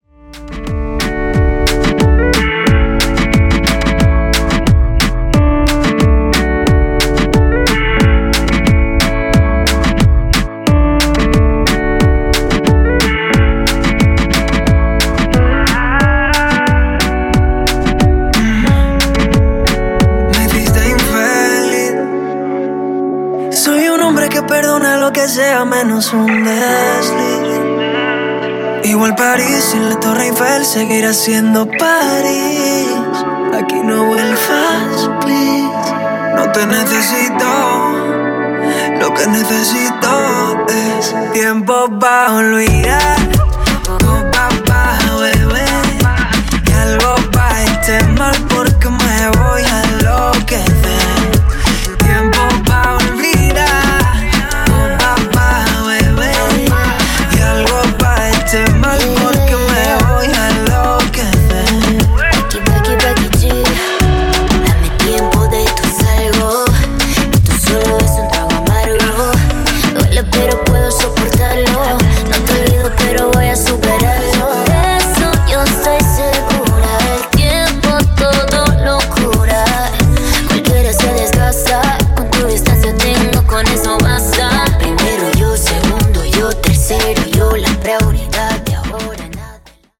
Urbano Afro Reton)Date Added